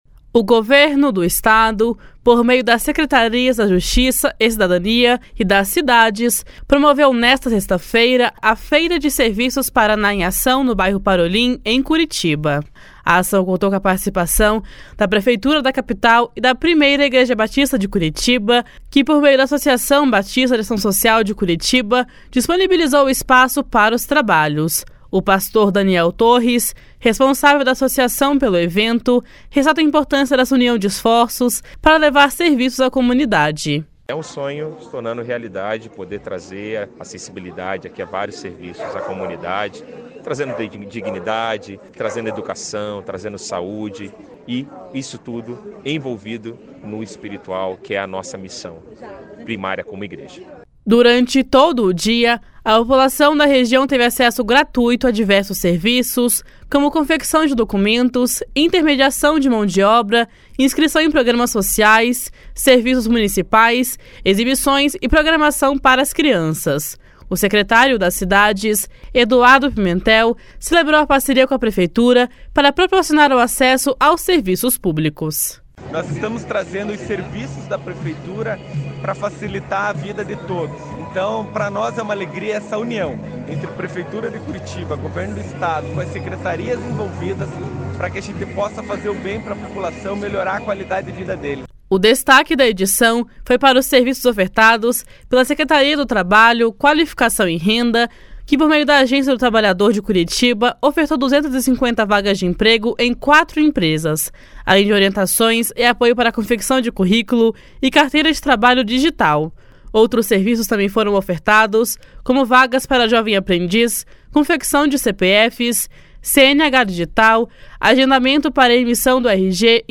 Durante todo o dia, a população da região teve acesso gratuito a diversos serviços, como confecção de documentos, intermediação de mão de obra, inscrição em programas sociais, serviços municipais, exibições e programação para as crianças. O secretário das Cidades, Eduardo Pimentel, celebrou a parceria com a prefeitura para proporcionar o acesso aos serviços públicos. // SONORA EDUARDO PIMENTEL //